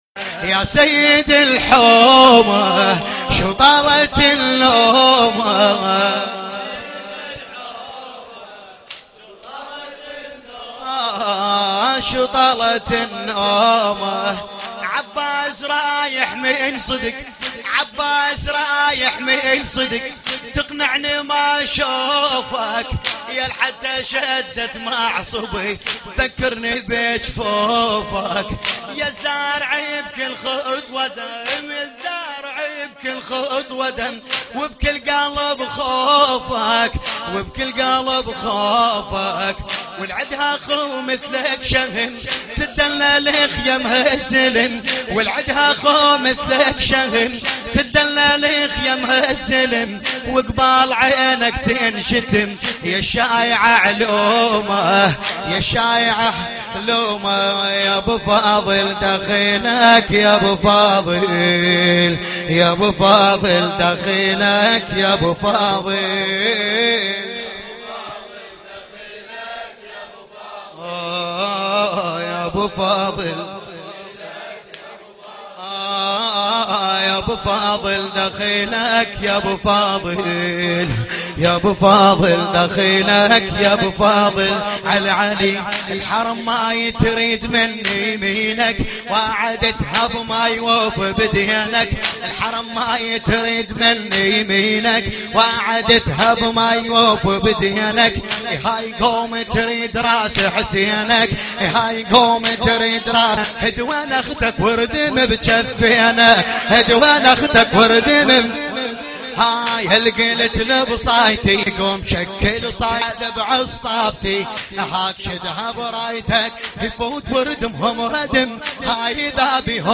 ملف صوتی عباس رايح من صدك بصوت السيد فاقد الموسوي
لطميات